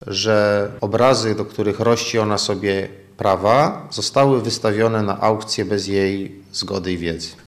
– Wątpliwości jednak mają śledczy, do których tuż przed licytacją zadzwoniła mieszkanka Szczecina – mówi prokurator Damian Kordykiewicz.